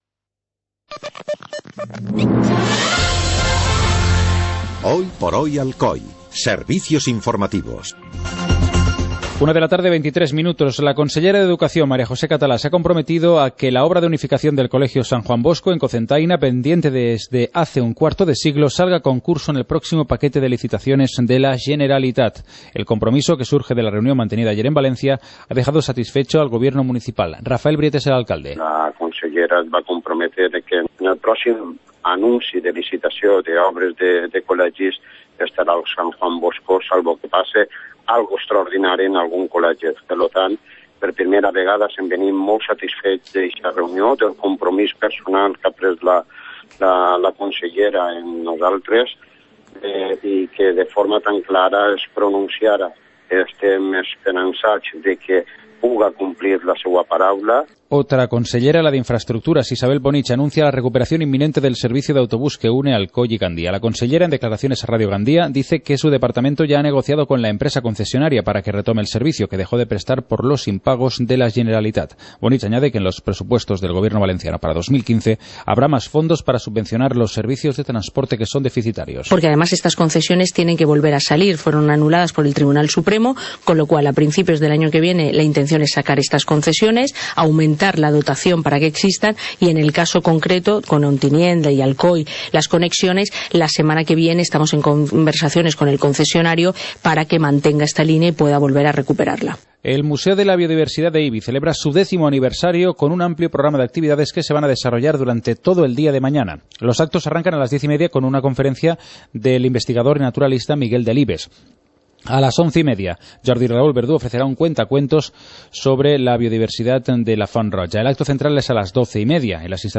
Informativo comarcal - viernes, 24 de octubre de 2014